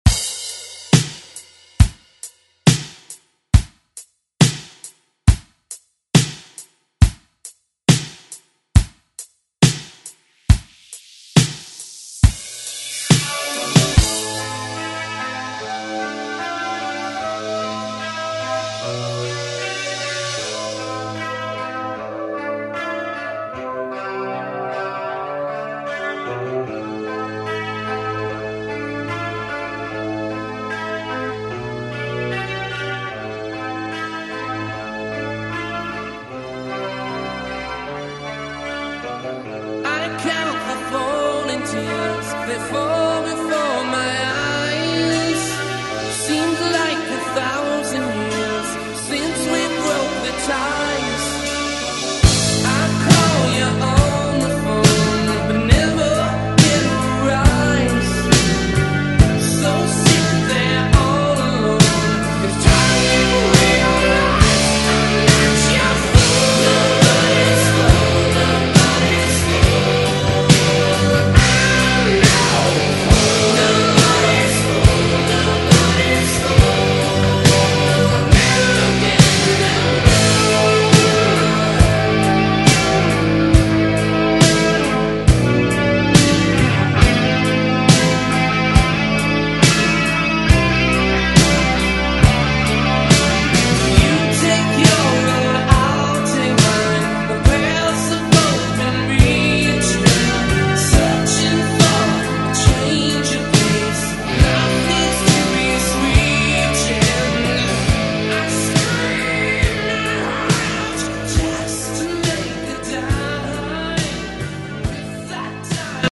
BPM: 69 Time